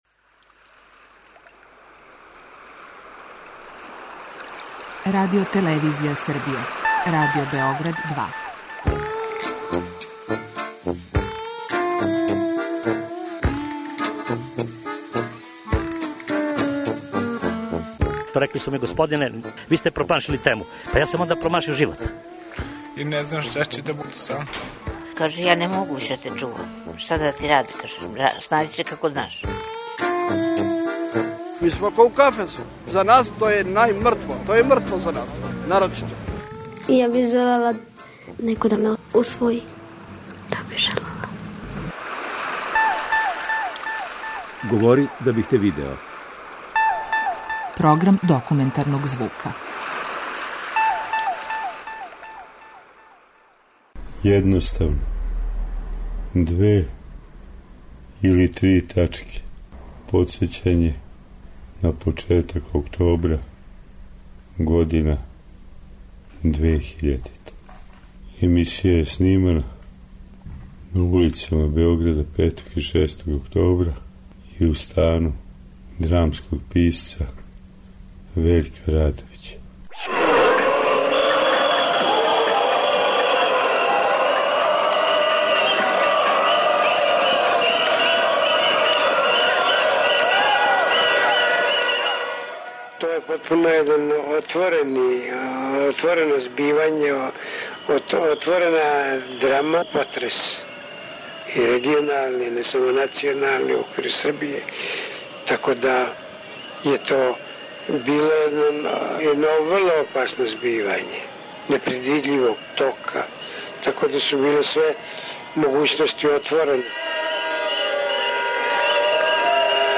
Документарни програм
преузми : 10.75 MB Говори да бих те видео Autor: Група аутора Серија полусатних документарних репортажа, за чији је скупни назив узета позната Сократова изрека: "Говори да бих те видео".
Подсећање на 5. октобар 2000. године. Емисија је снимана на улицама Београда 5. и 6. октобра 2000.